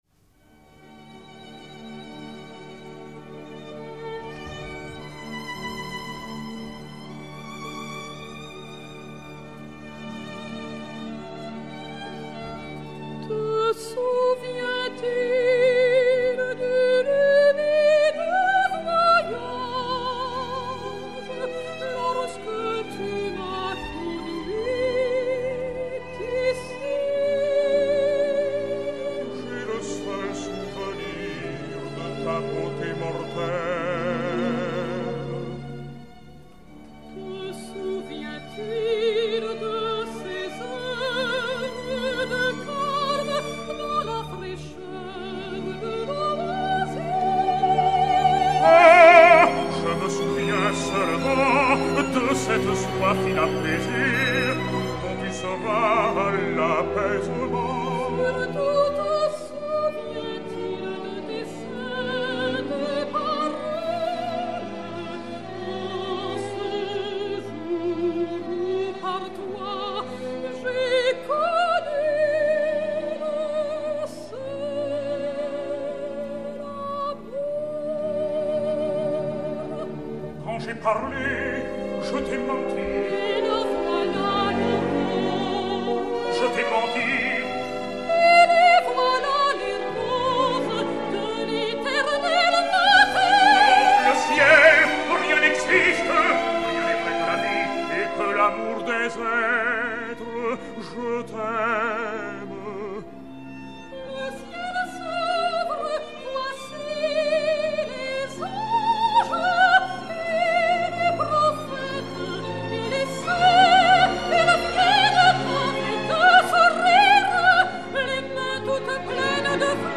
enr. en public à Paris le 27 août 1959